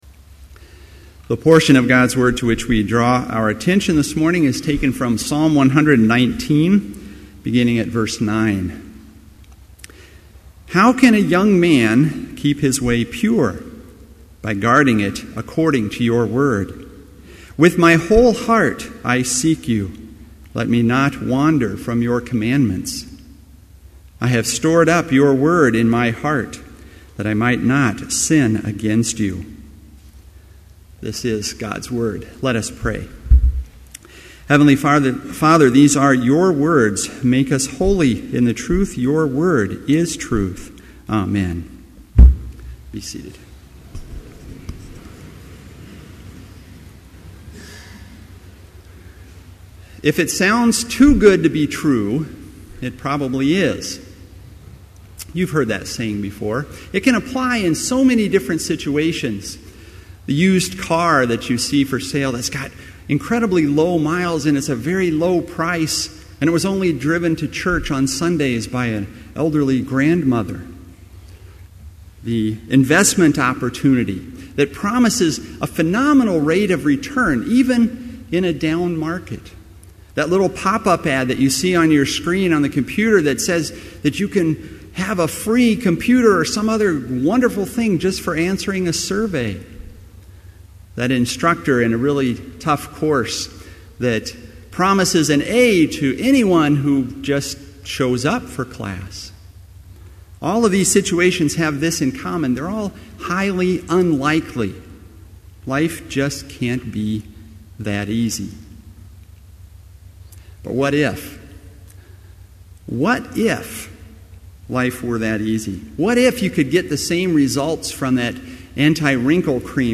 Chapel worship service held on October 13, 2011, BLC Trinity Chapel, Mankato, Minnesota, (audio available)
Complete service audio for Chapel - October 13, 2011